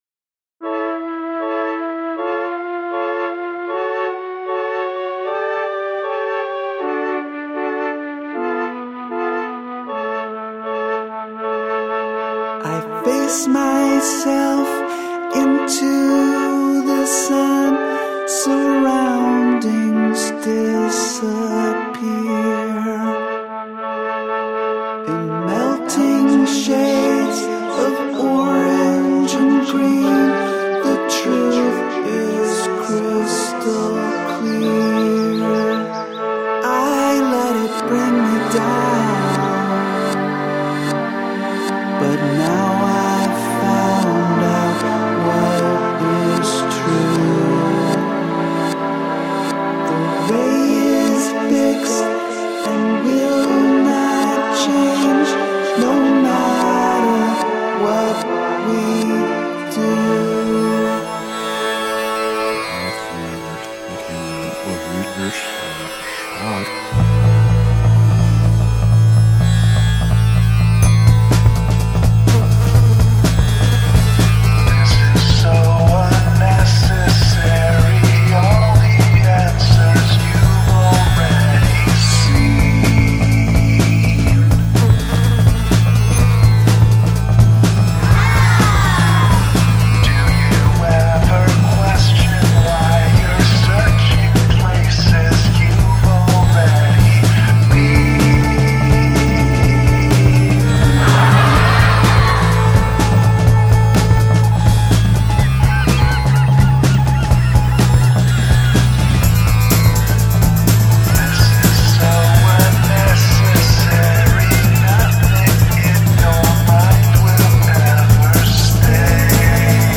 Psychedelic